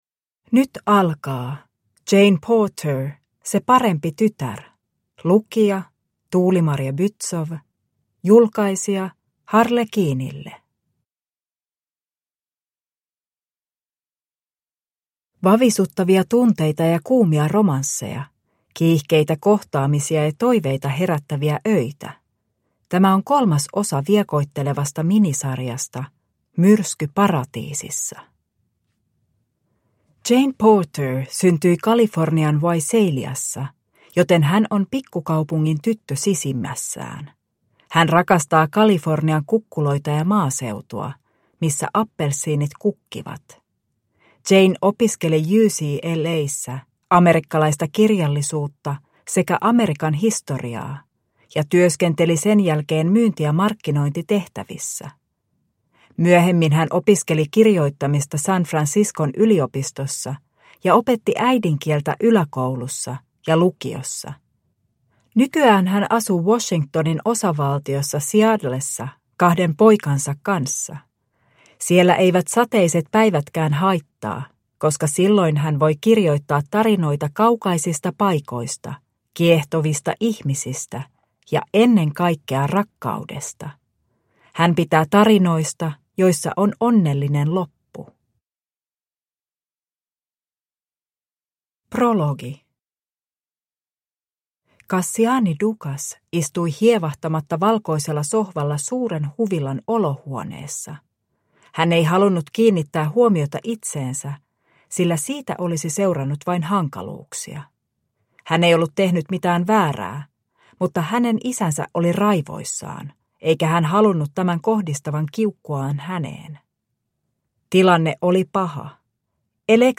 Se parempi tytär (ljudbok) av Jane Porter